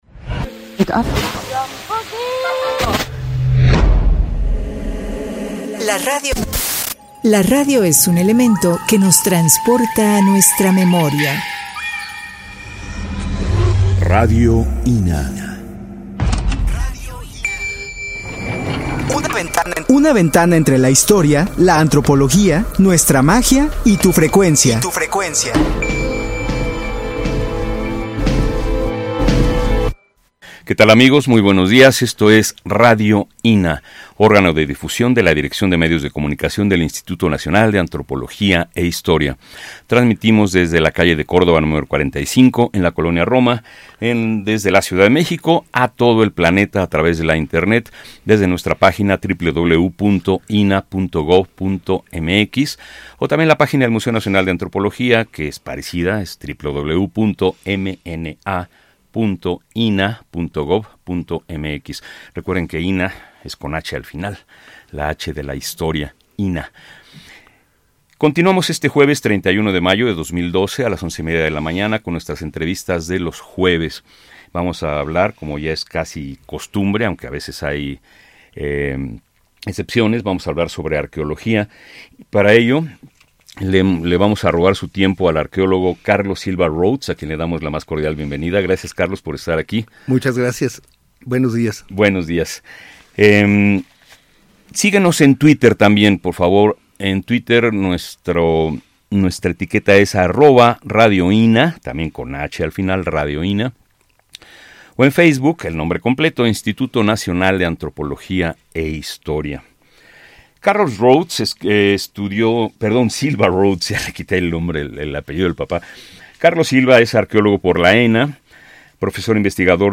entrevista_18-Service File.mp3